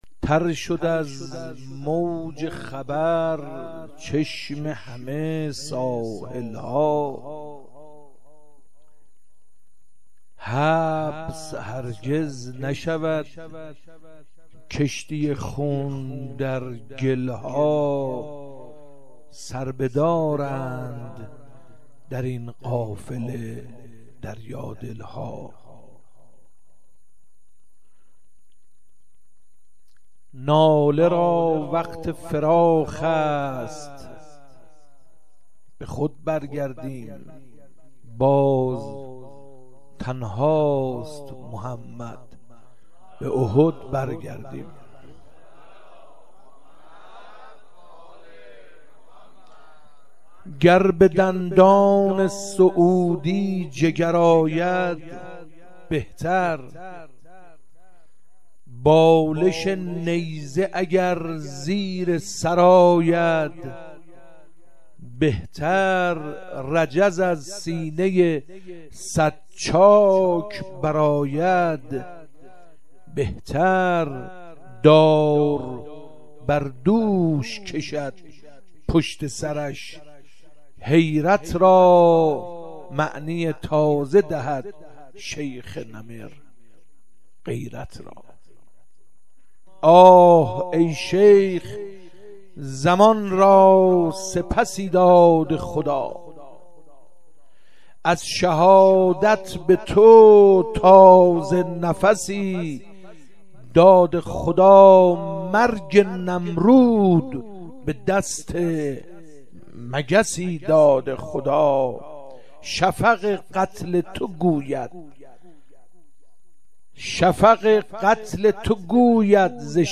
صوت/ شعر خوانی